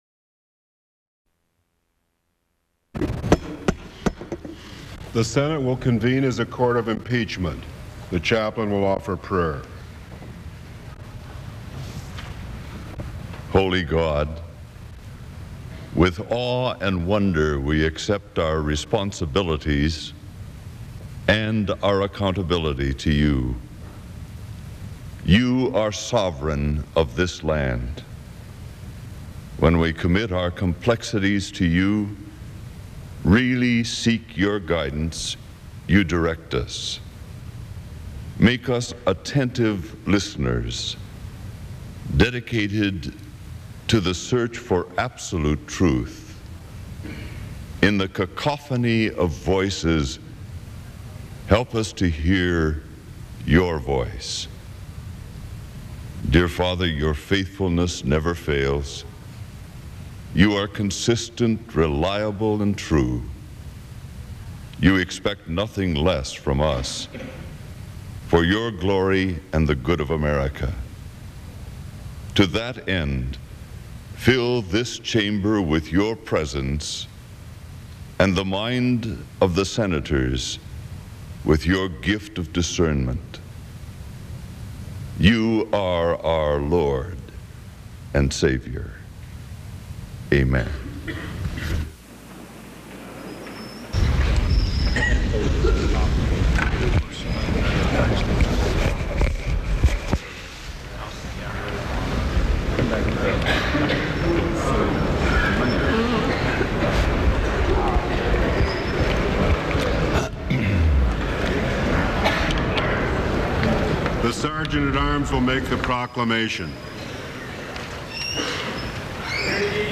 Bill McCollum (R-FL) testifies in the impeachment of President Bill Clinton. Other speakers include Chief Justice William Rehnquist, Senate Chaplain Lloyd Ogilvie, Sergeant at Arms James Ziglar, and Senator Trent Lott.
Broadcast on NPR, January 15, 1999.